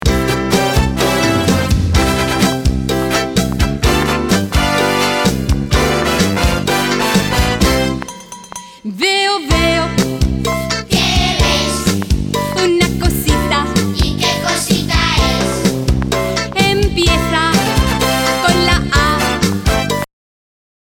Kids songs like "RiuLand"